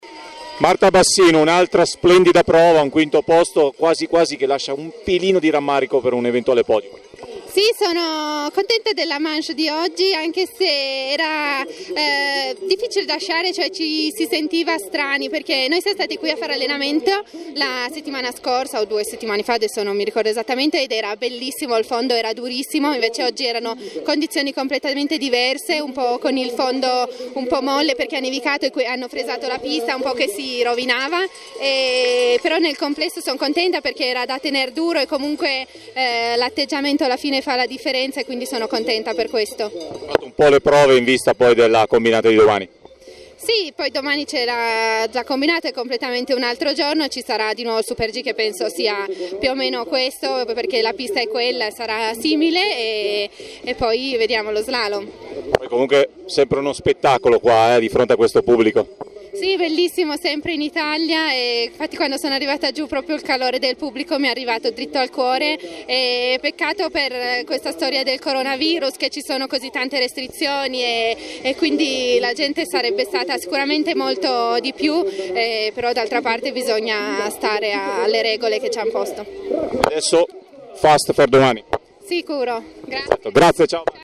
Intervista audio con Marta Bassino
la-thuile-2020-super-g-marta-bassino.mp3